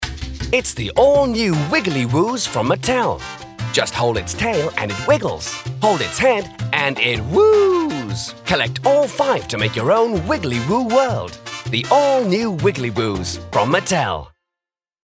Sprecher kabine mit Neumann U87 AI Joe Meek Pre amp Logic Software Music Taxi /APT-X
britisch
Sprechprobe: Sonstiges (Muttersprache):
Warm, authoritative sound - ideal for commercials and presentation.
Very flexible voice extremely well suited for animation and character work